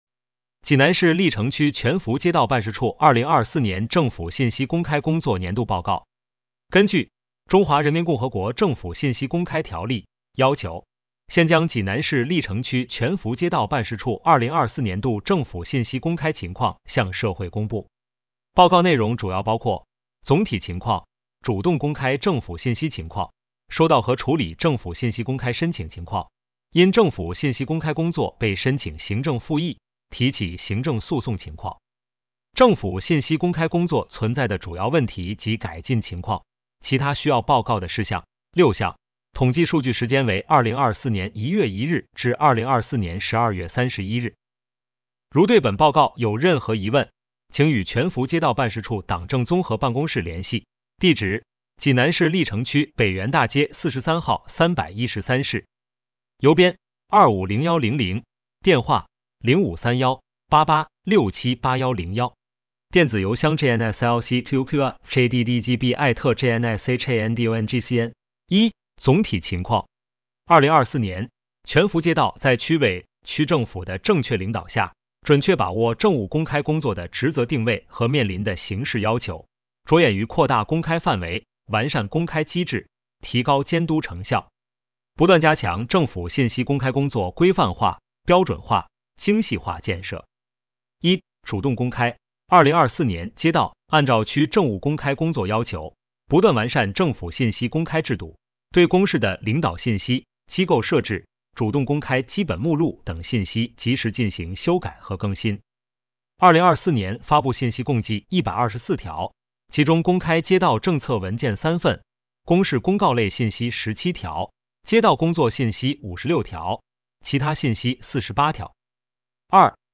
全福街道办事处 - 有声朗读 - 音频解读：济南市历城区人民政府全福街道办事处关于印发《全福街道建成区易发(高发)新增违法建设风险住宅小区专项整治行动实施方案》的通知